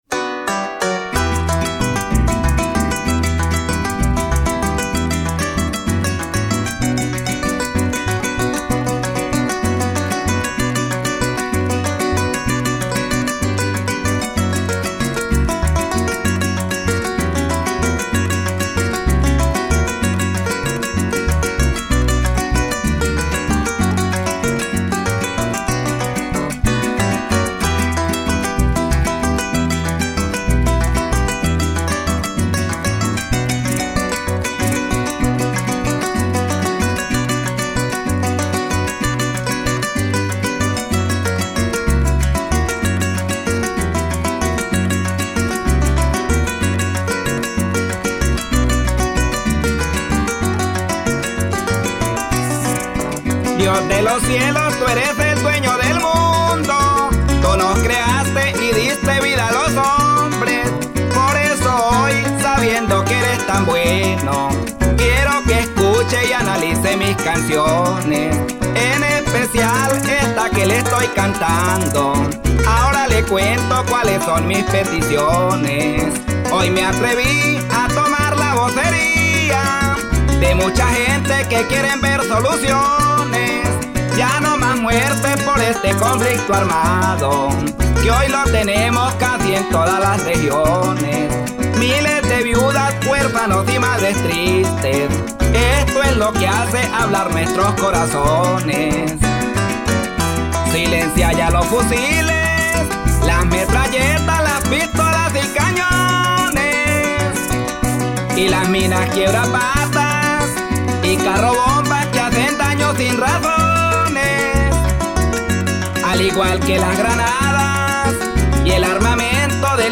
Canción
voz.
arpa.
maracas.
bajo.